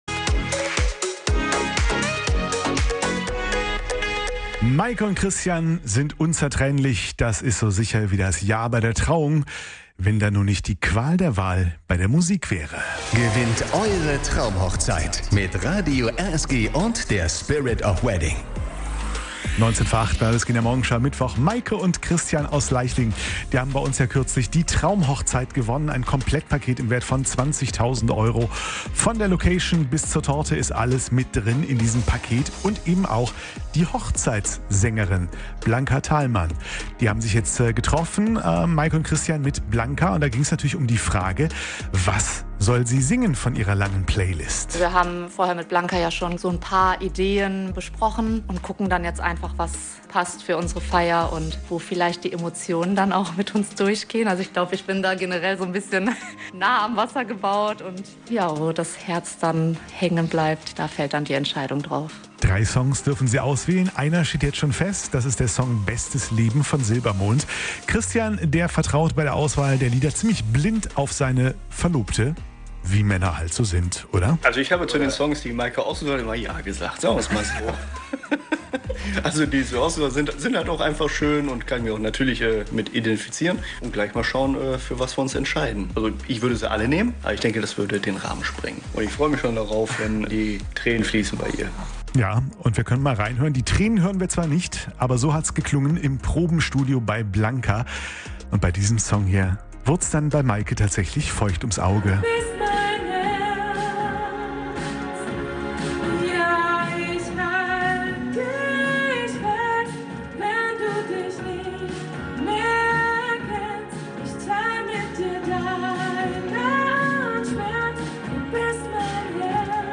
Hochzeitssängerin
zur Live-"Hörprobe" im Probenkeller